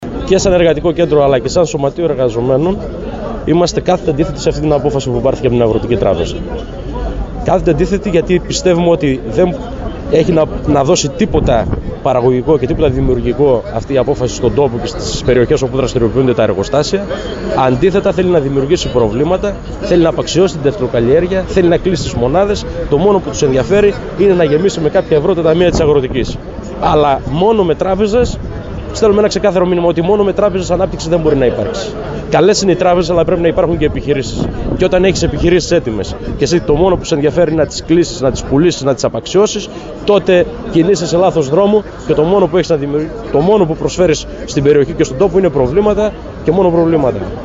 Την αντίθεσή τους σχετικά με τα σχέδια της κυβέρνησης να προχωρήσει σε πώληση της Αγροτικής Τράπεζας Ελλάδας εξέφρασαν σήμερα οι αγρότες του Βορείου Έβρου σε συγκέντρωση διαμαρτυρίας που διοργάνωσε η Ομοσπονδία Αγροτικών Συλλόγων Έβρου “Η Ενότητα” μπροστά από την Αγροτική Τράπεζα στην Ορεστιάδα.